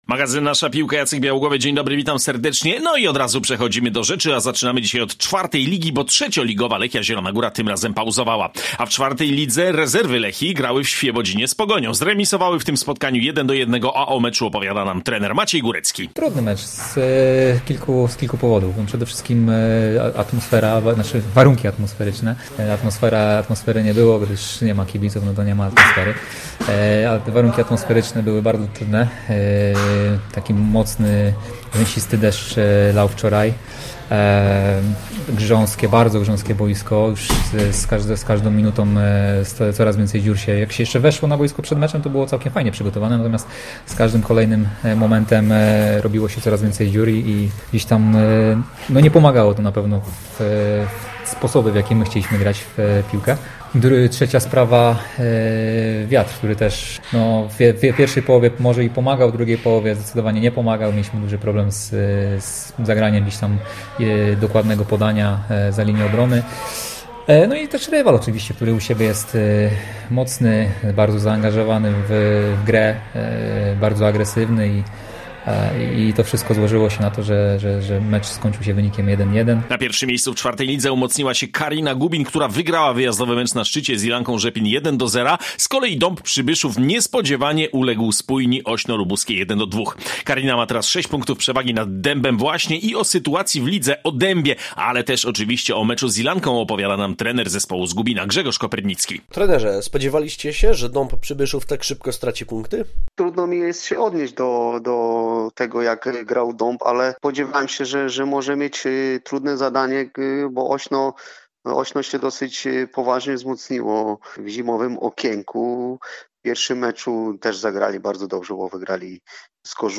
Zapraszamy na cotygodniowy magazyn piłkarski Nasza piłka. 4 liga, okręgówka i klasa A rozegrały swe mecze, zaczęły wiosnę i…wiszą w związku z lockdownem. Ale byliśmy na kilku stadionach!